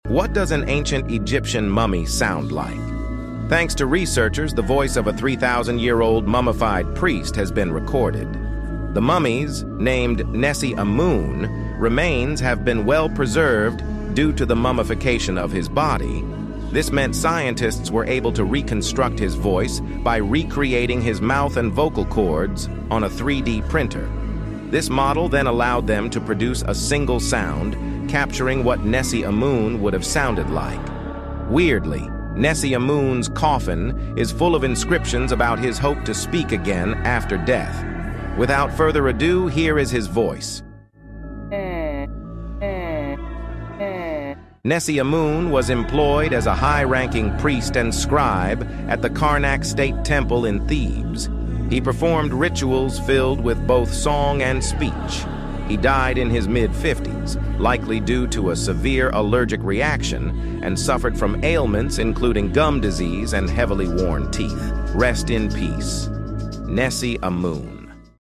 The Sound Of An Egyptian Mummy